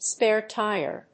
アクセントspáre tíre [《主に英国で用いられる》 týre]
音節spàre tíre